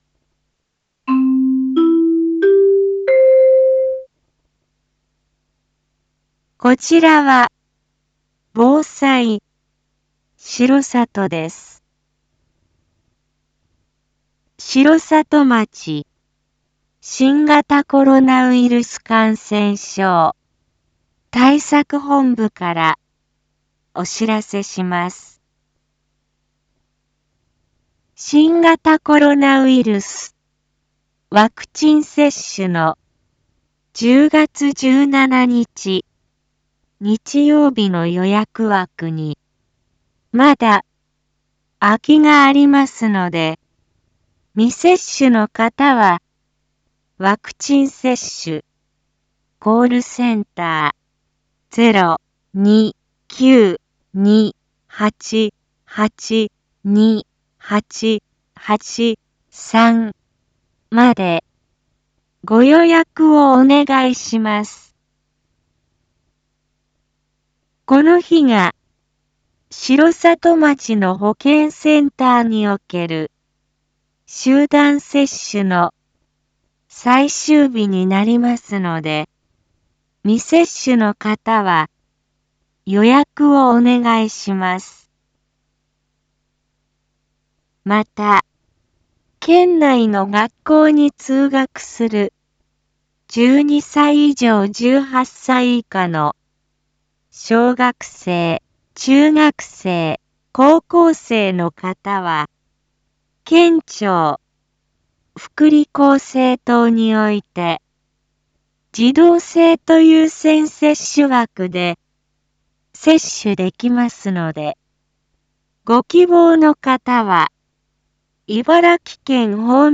一般放送情報
Back Home 一般放送情報 音声放送 再生 一般放送情報 登録日時：2021-10-15 07:02:37 タイトル：R3.10.15 7時放送 インフォメーション：こちらは防災しろさとです。 城里町新型コロナウイルス感染症対策本部からお知らせします。